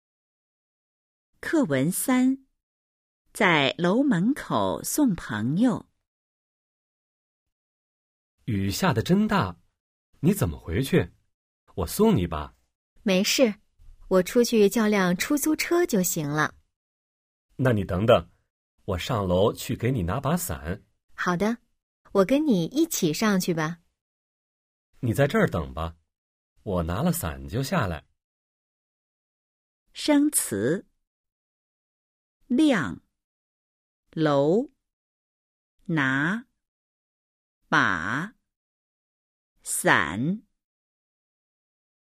Bài hội thoại 3: 🔊 在楼门口送朋友 – Tiễn bạn ở trước cửa tòa nhà  💿 02-03